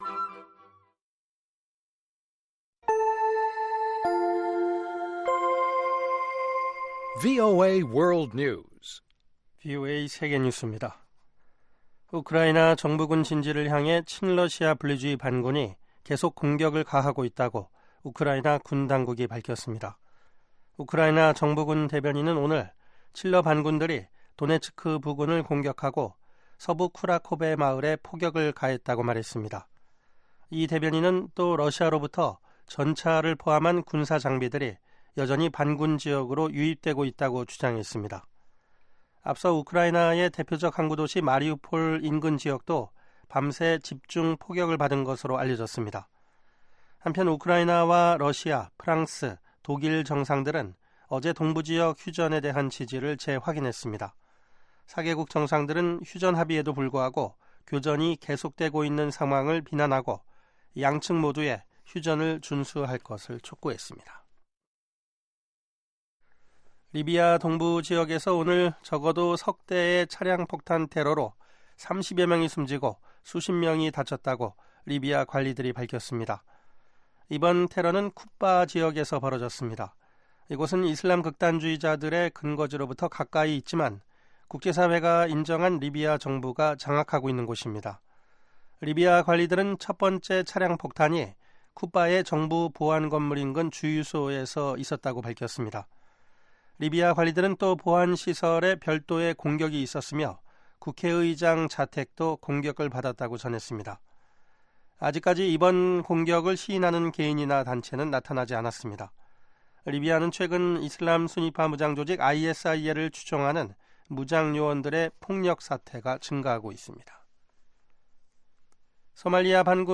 VOA 한국어 방송의 간판 뉴스 프로그램 '뉴스 투데이' 3부입니다. 한반도 시간 매일 오후 11시부터 자정까지 방송됩니다.